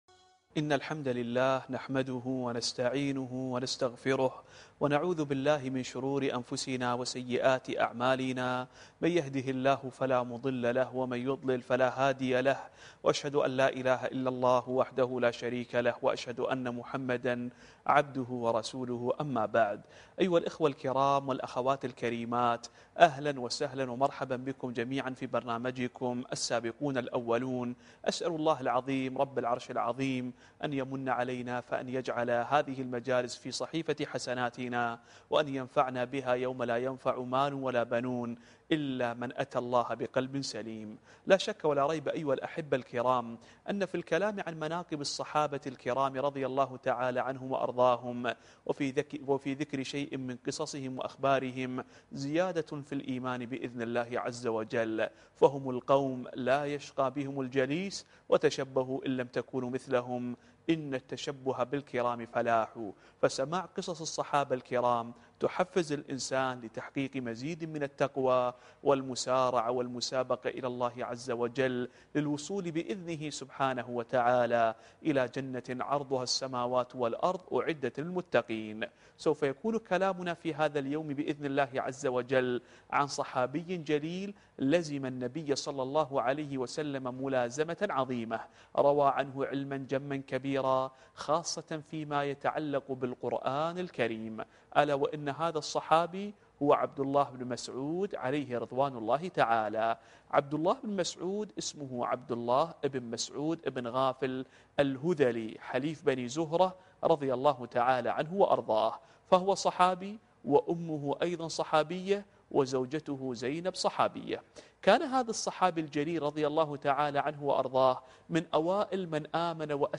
الدرس الثالث والعشرون